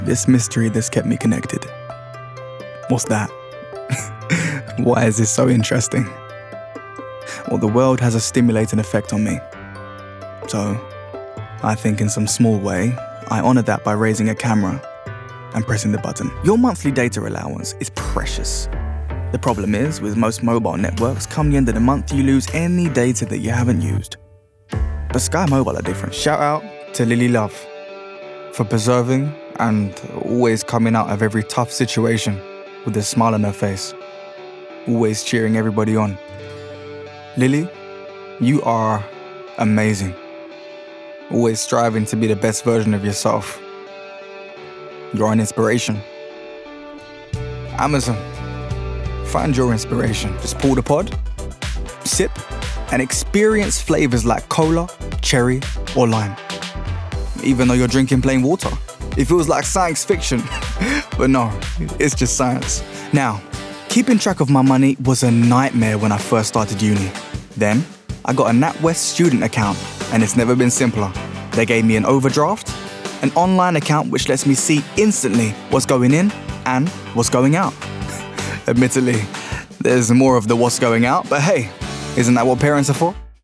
Commercial Reel
RP ('Received Pronunciation')
Commercial, Bright, Upbeat, Cool